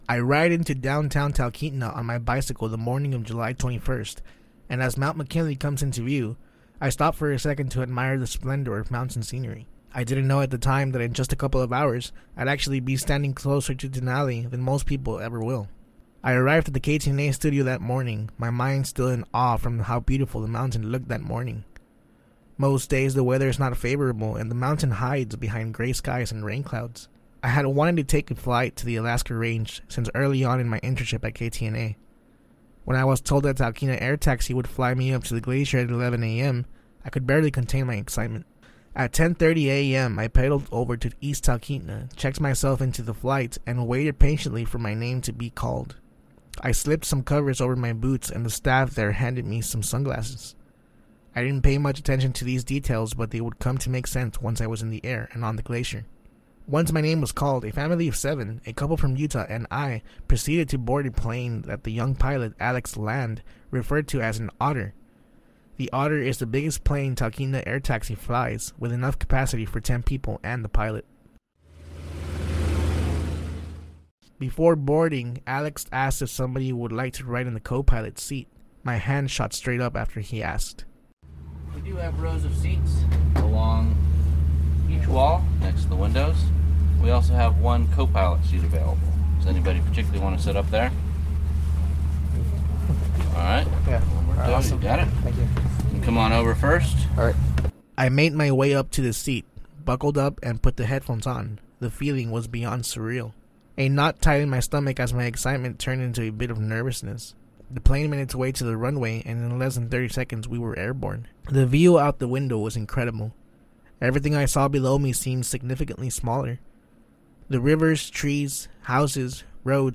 He took a recorder along, and has this report: